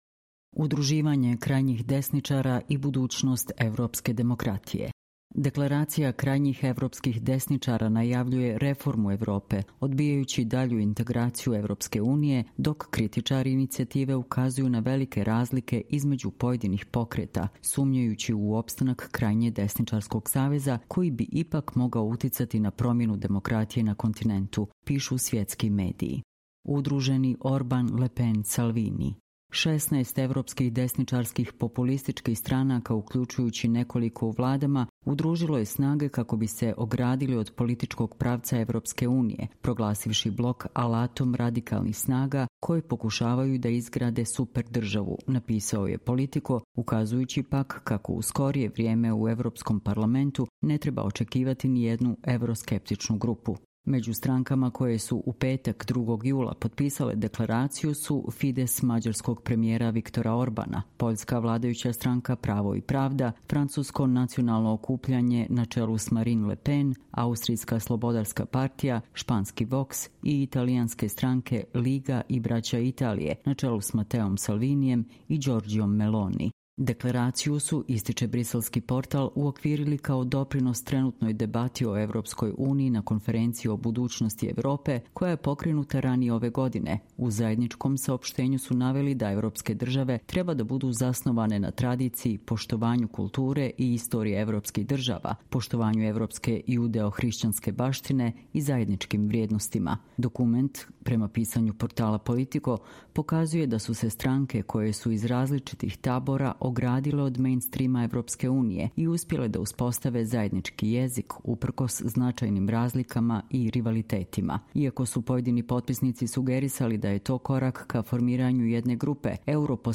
Čitamo vam: Udruživanje krajnjih desničara i budućnost evropske demokratije